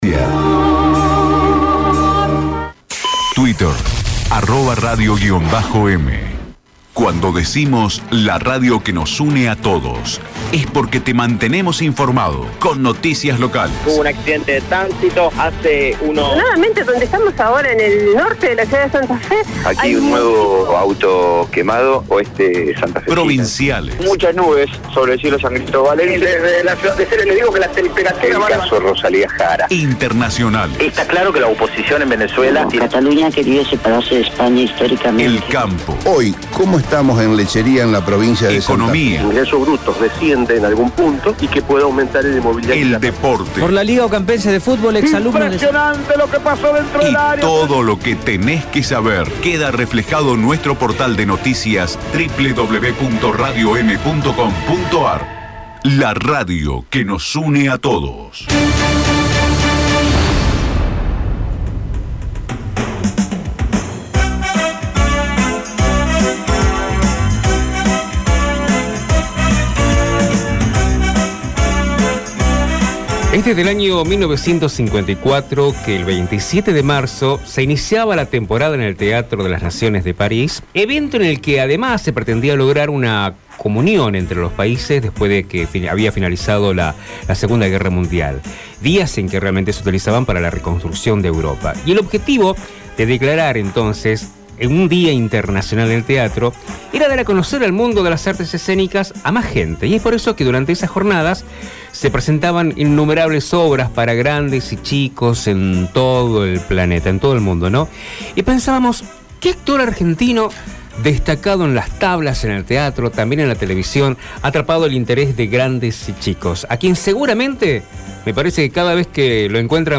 En el programa Dale Que Vamos se realizó una interesante nota a Arturo Puig, un reconocido actor y director argentino.